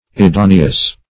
Meaning of idoneous. idoneous synonyms, pronunciation, spelling and more from Free Dictionary.
Search Result for " idoneous" : The Collaborative International Dictionary of English v.0.48: Idoneous \I*do"ne*ous\, a. [L. idoneus.]